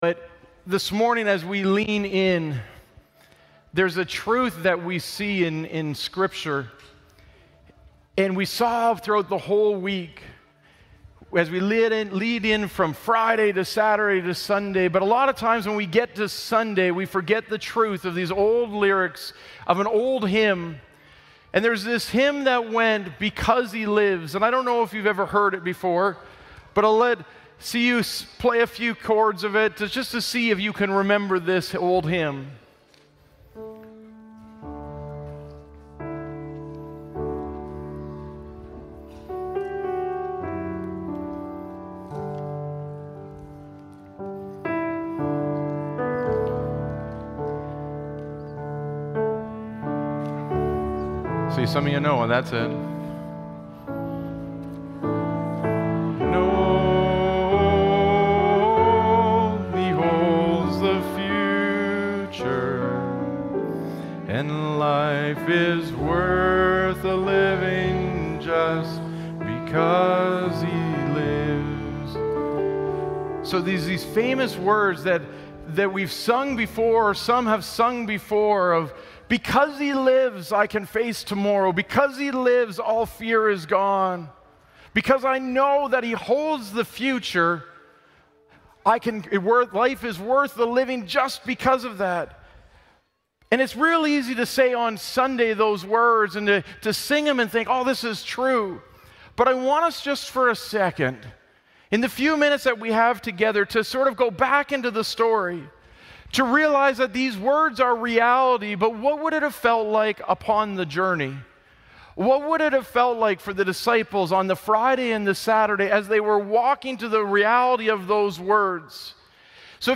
Glad Tidings Church (Sudbury) - Sermon Podcast Because He Lives Play Episode Pause Episode Mute/Unmute Episode Rewind 10 Seconds 1x Fast Forward 30 seconds 00:00 / 00:22:15 Subscribe Share RSS Feed Share Link Embed